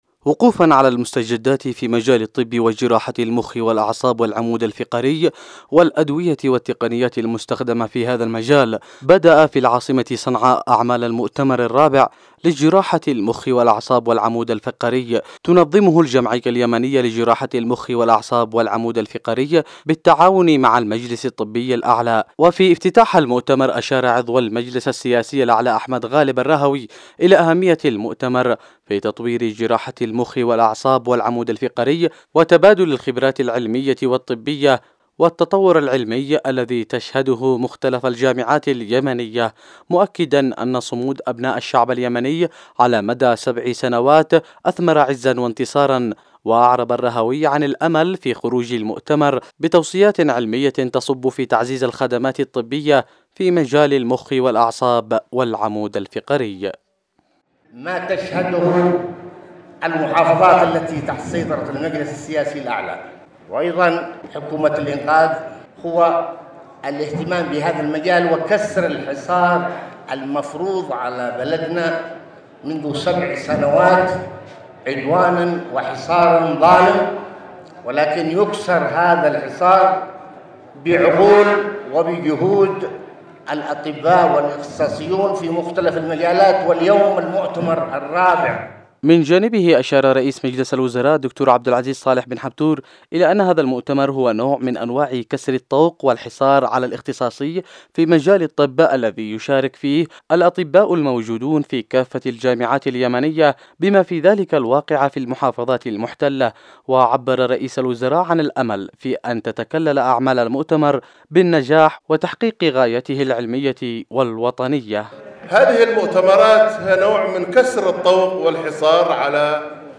تقرير الخبر